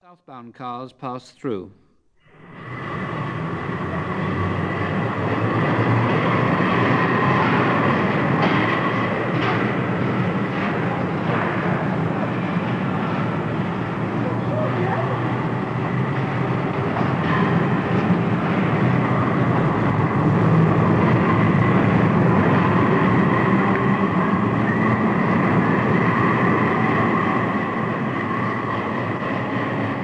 Londons Last Trams Stage 7 Live recordings
Stage 7 Kingsway Tram Subway